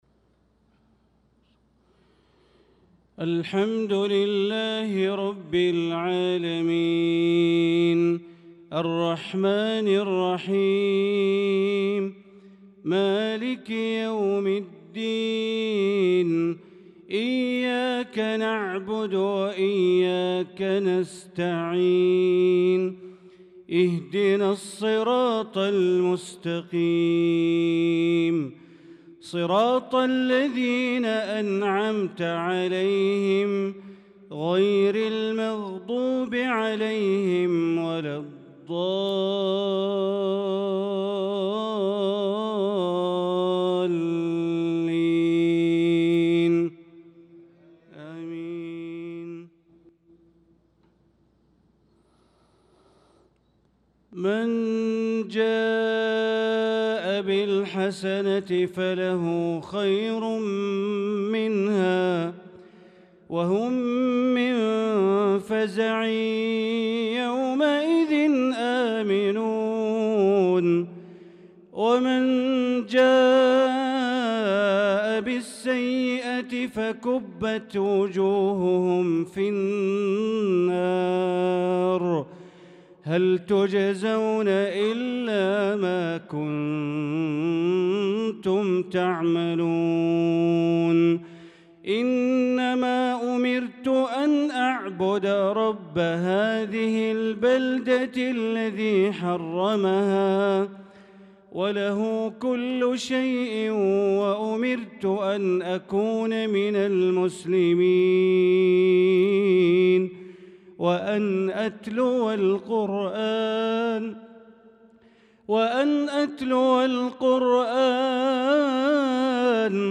صلاة العشاء للقارئ بندر بليلة 29 شوال 1445 هـ
تِلَاوَات الْحَرَمَيْن .